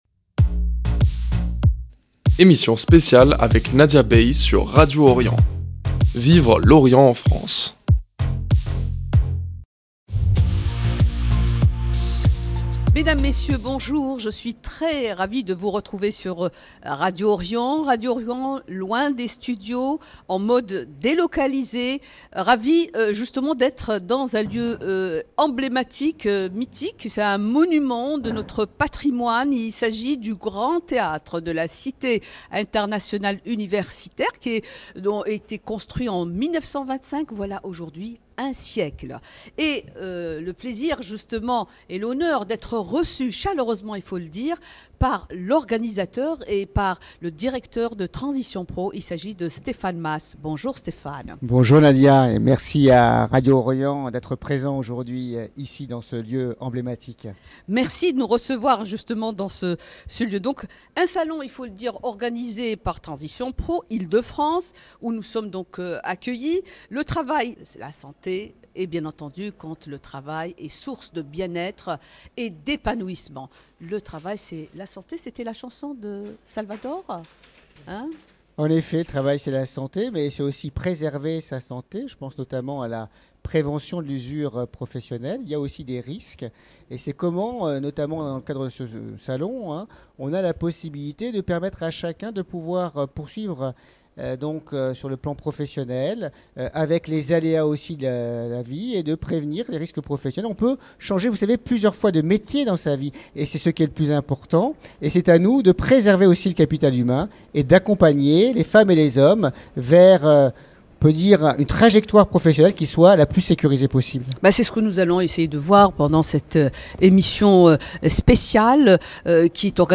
Emission spéciale Changer de vie professionnelle : quelles sont les clés pour réussir sa reconversion ?